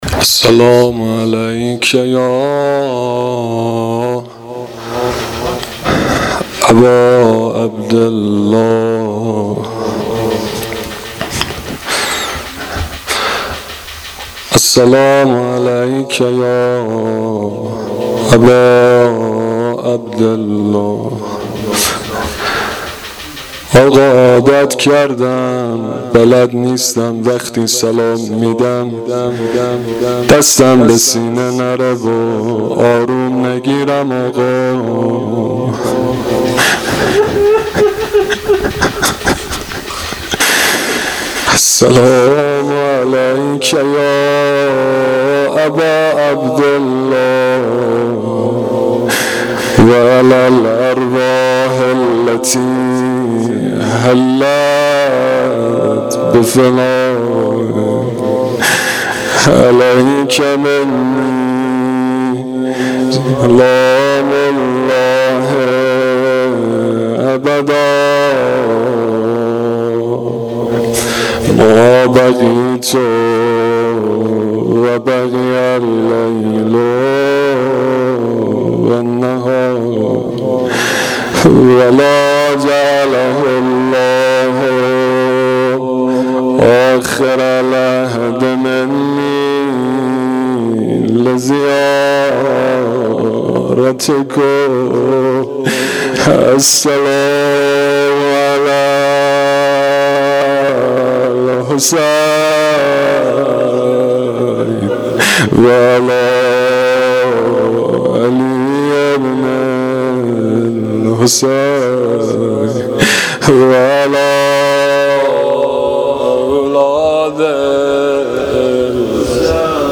روضه شب دوم محرم الحرام 1395